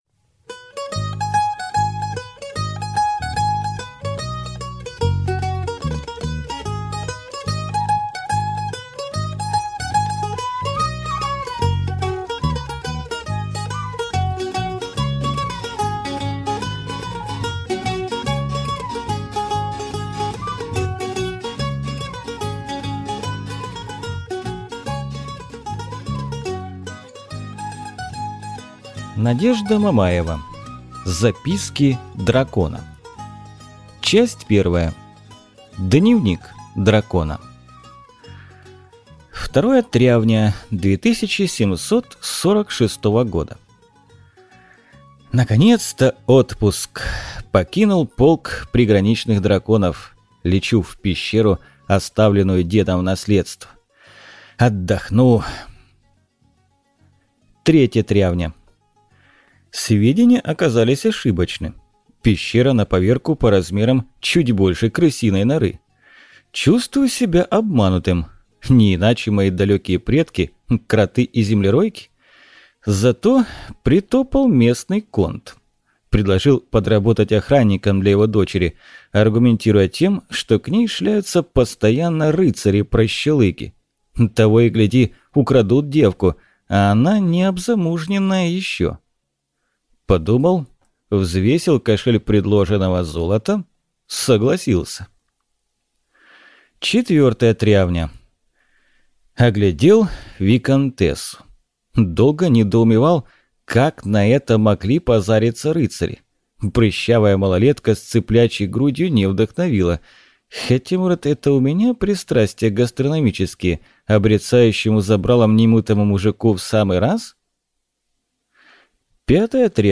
Аудиокнига Записки дракона | Библиотека аудиокниг